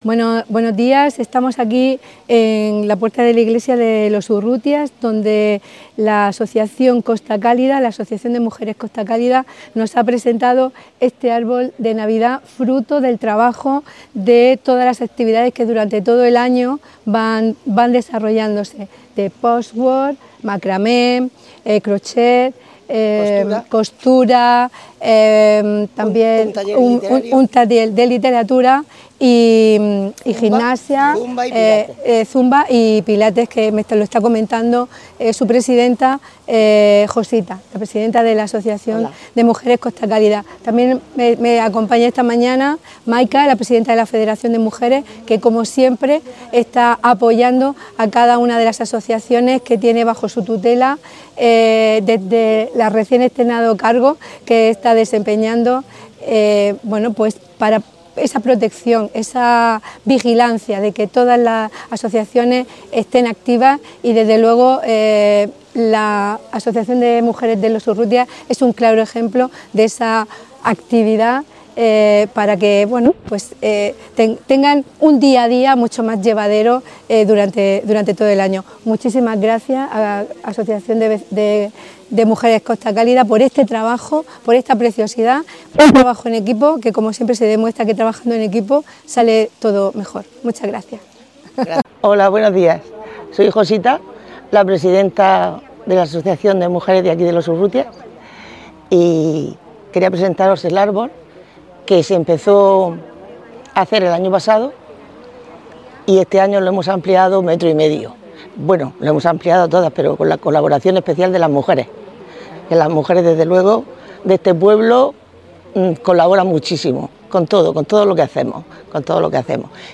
Enlace a Declaraciones de la concejal Francisca Martínez y representantes de la Asociación de Mujeres Costa Cálida